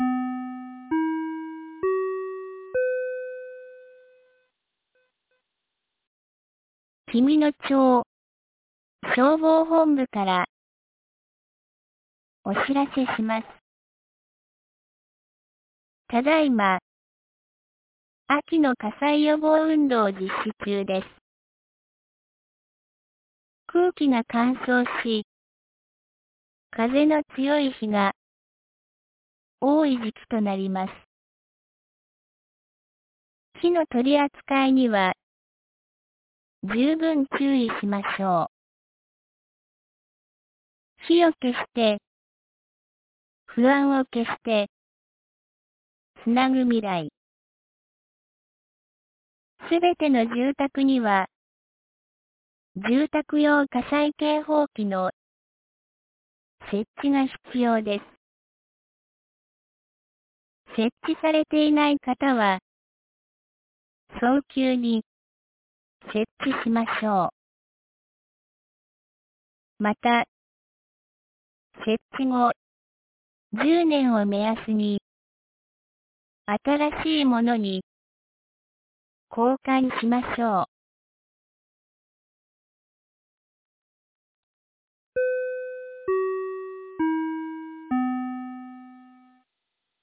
2023年11月14日 17時06分に、紀美野町より全地区へ放送がありました。